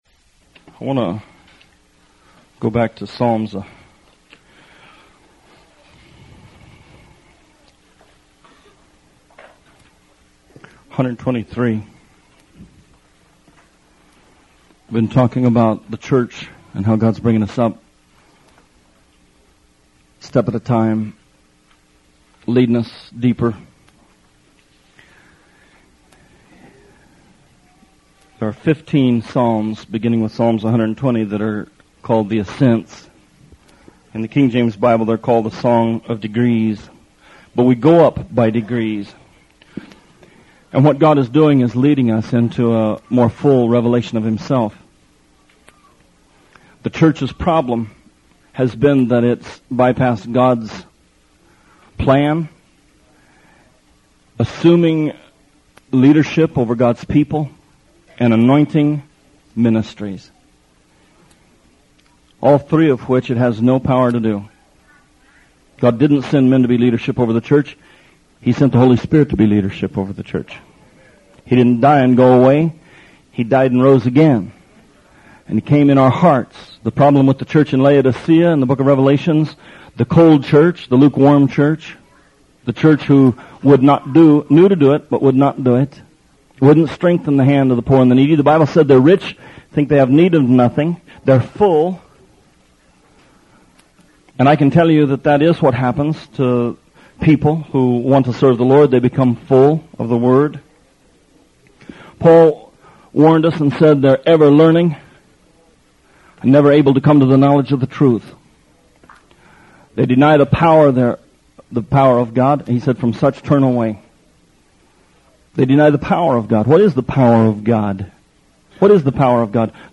Songs of Degrees: This is a sermon series about Psalms 120 through 134.